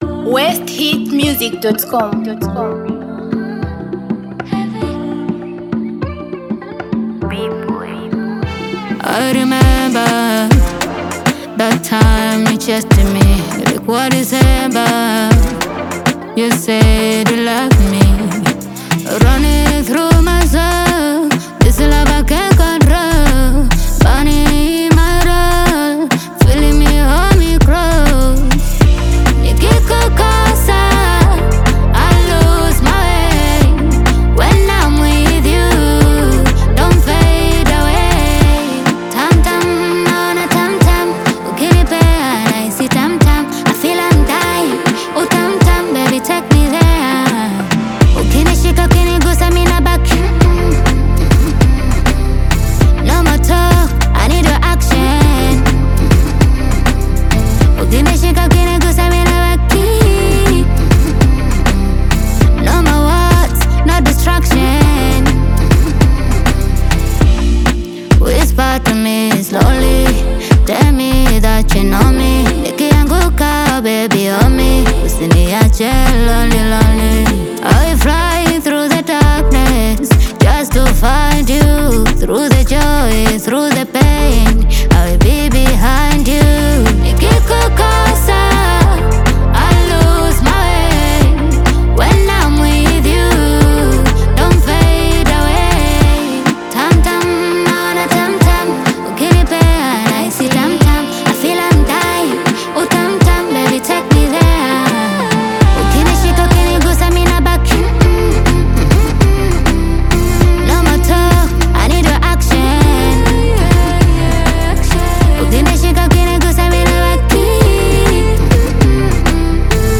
Category: Tanzania Music